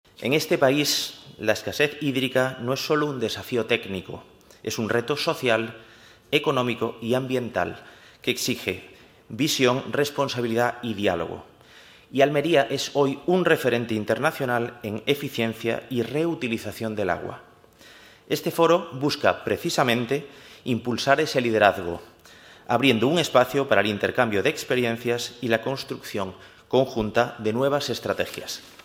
El I Foro Agroalimentario de Tribuna Andalucía reúne a las tres administraciones en torno al agua como motor de innovación, sostenibilidad y desarrollo agrícola